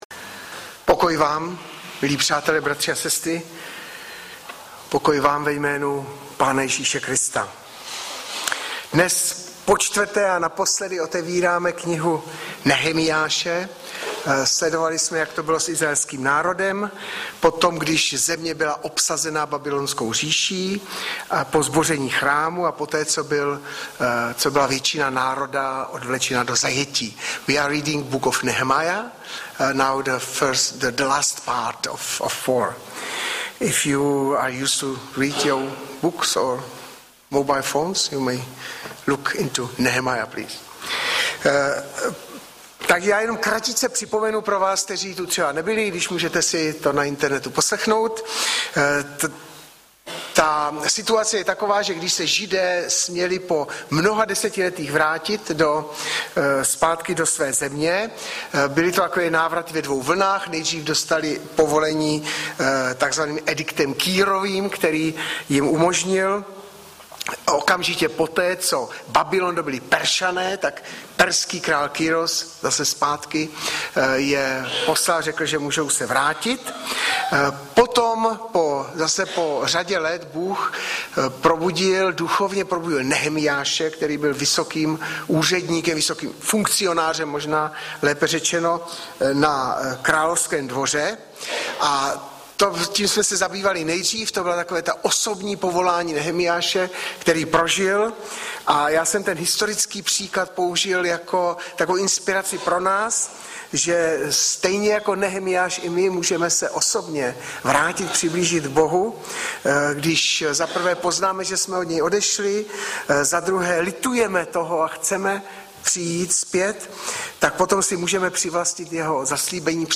Audiozáznam kázání si můžete také uložit do PC na tomto odkazu.